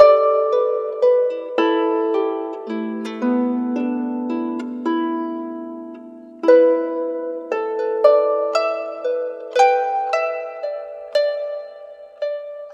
Harp01_113_G.wav